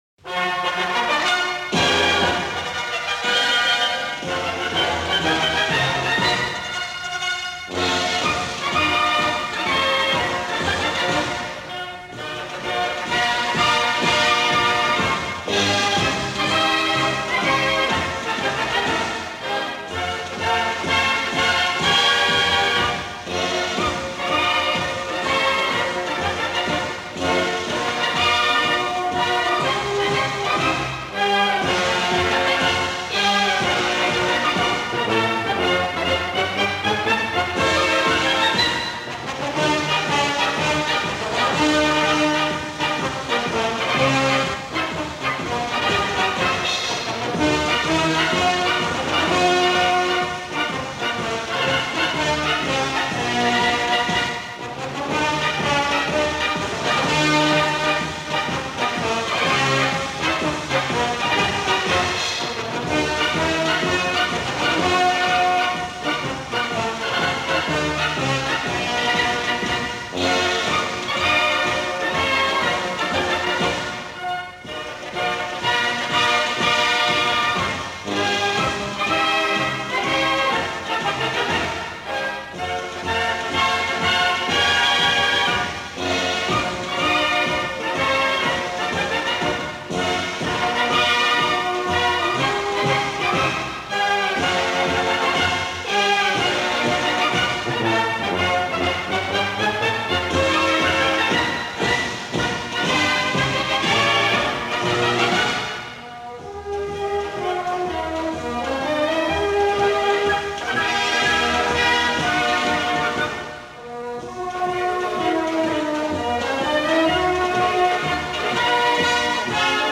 für Blaskapelle